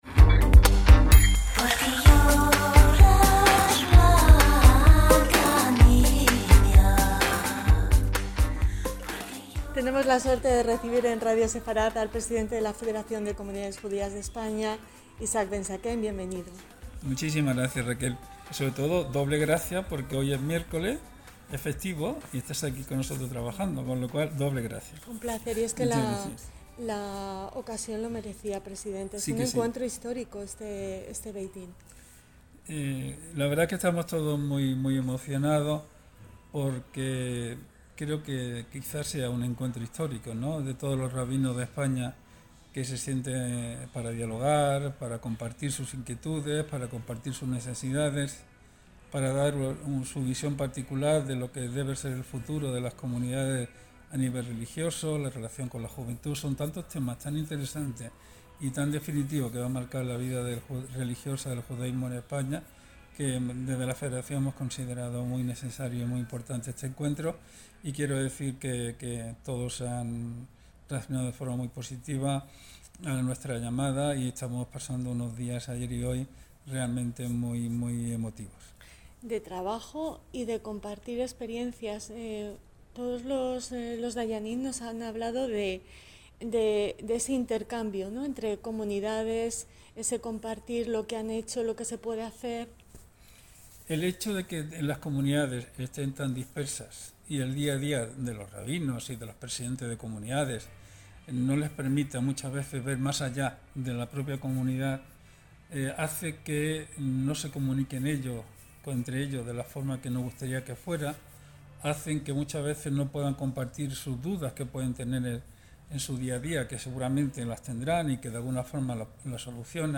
Tuvimos la oportunidad de hablar con ellos